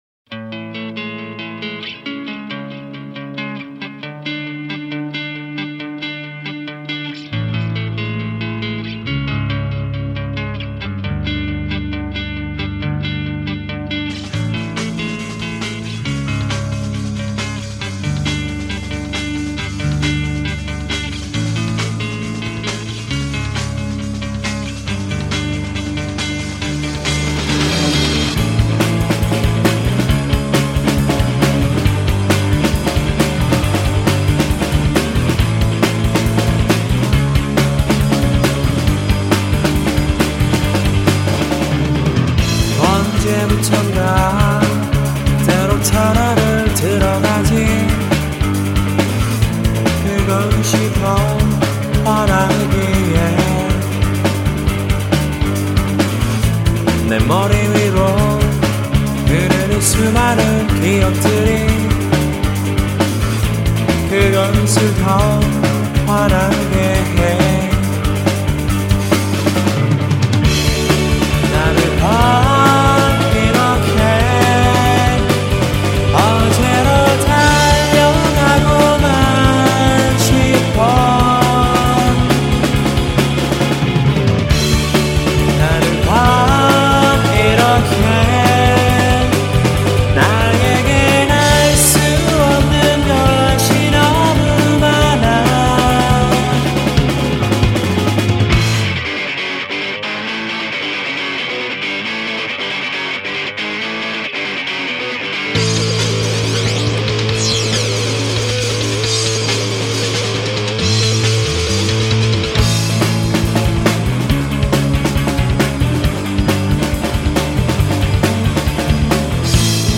약간의 단조로 된 음이 희안하다며..
익숙치 않은 음과 리듬의 조합이어서 신선하게 들었던것 같아..